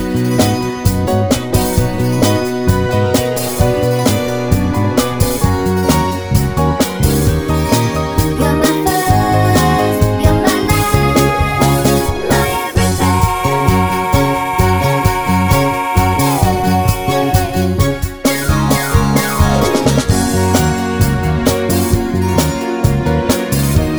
One Key Up Disco 3:16 Buy £1.50